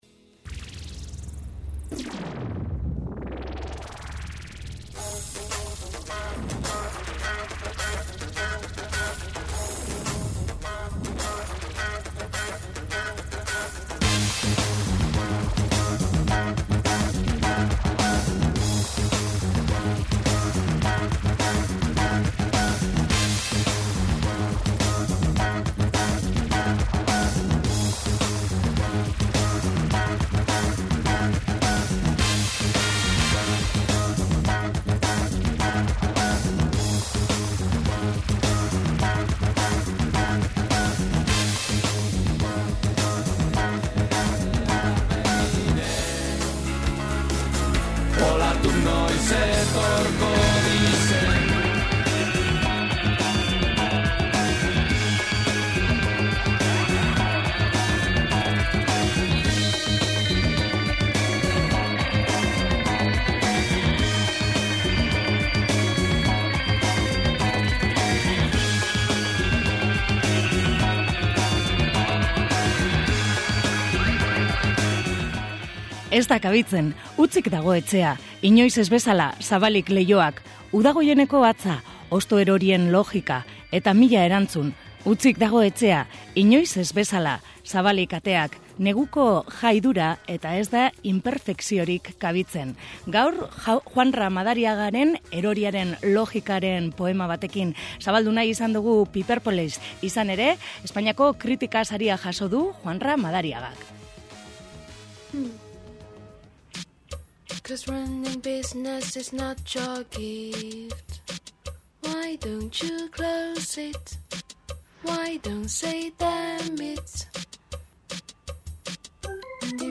Talde 2008an sortu zen donosti inguruan, diskoa grabatu berri dute eta kantu berriak aurkezten dabiltza. Ska, reggaea, zingaroa eta rock doinuak biltzen dituzte.